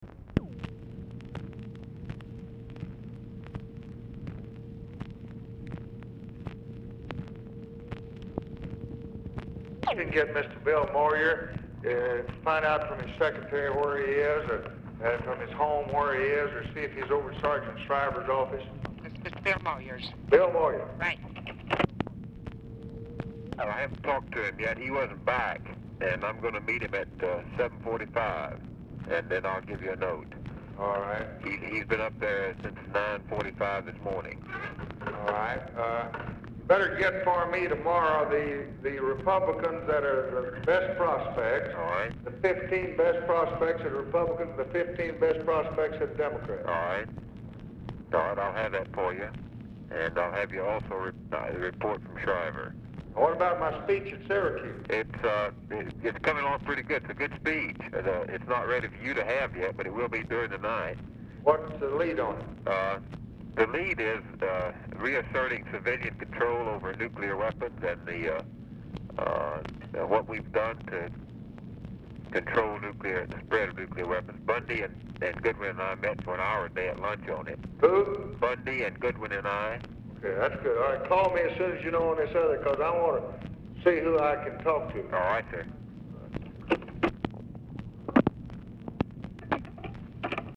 Telephone conversation # 4646, sound recording, LBJ and BILL MOYERS, 8/3/1964, 7:22PM
LBJ ASKS TELEPHONE OPERATOR TO PLACE CALL TO MOYERS; RECORDING OF MOYERS CALL STARTS AFTER CONVERSATION HAS BEGUN
Format Dictation belt